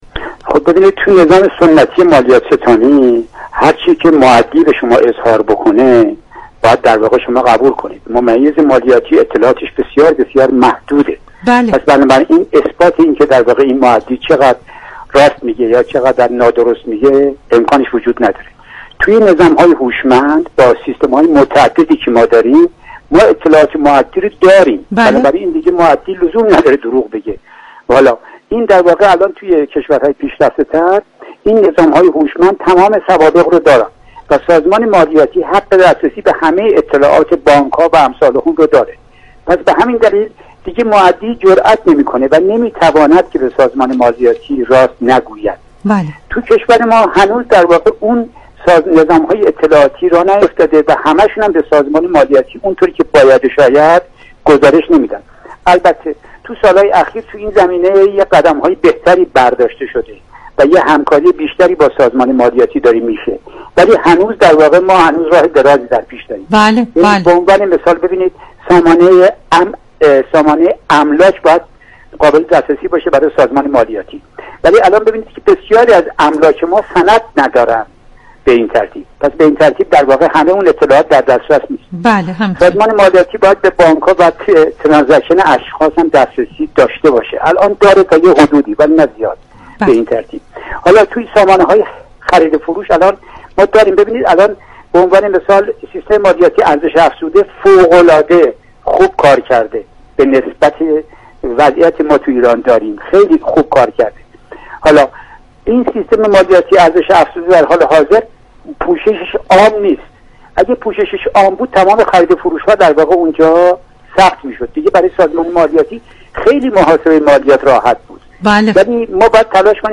در ادامه هادی خانی معاون امور مالیاتی با اشاره به دیگر مزایای این طرح افزود: در سیستم جدید و هوشمند اخذ مالیات مبتنی بر داده و اطلاعات دقیق است.